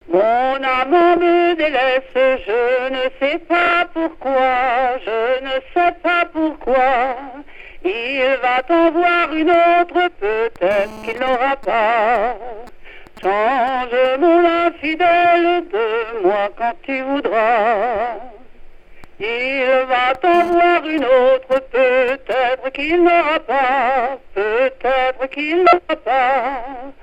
Chansons en dansant
Genre strophique
chansons traditionnelles